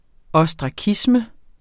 Udtale [ ʌsdʁɑˈkismə ]